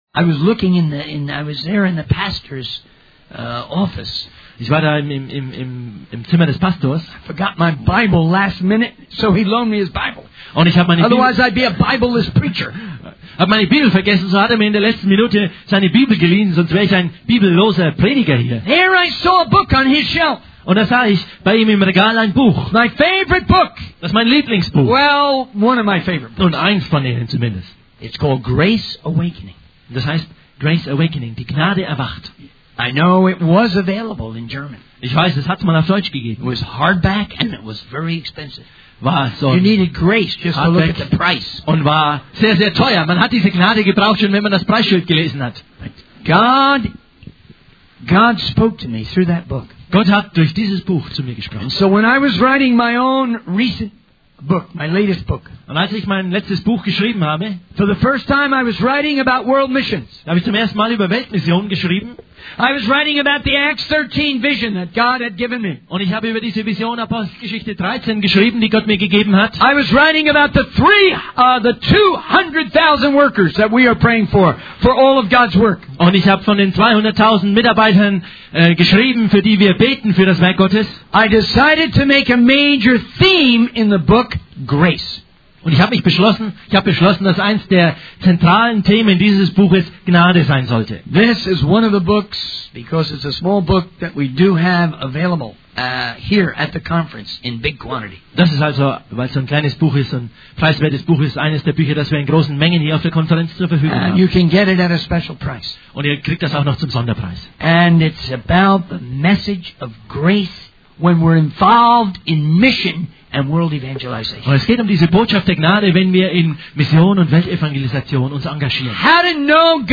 In this sermon, the speaker begins by reading Matthew 9:36-38, where Jesus is moved with compassion for the multitude and recognizes the need for more laborers in the harvest. The speaker shares his personal background, growing up in a non-Christian home and facing challenges.